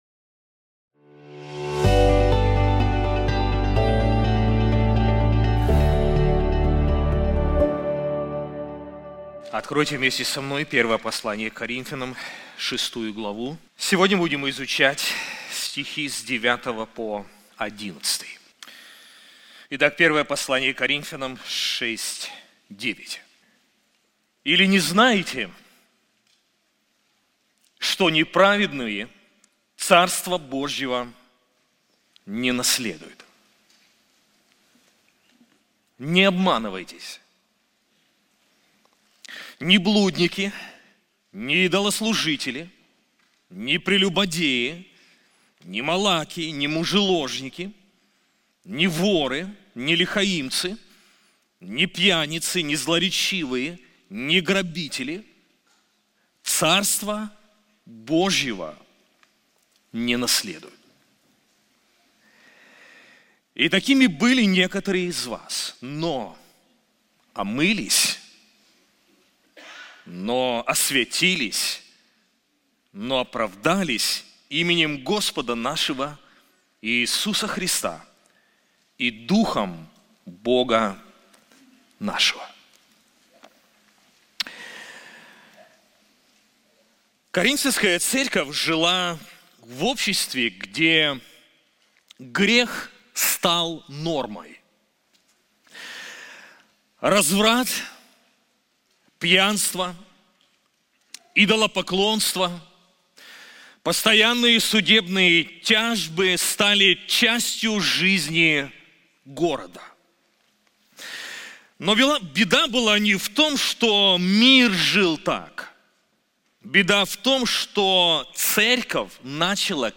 Проповеди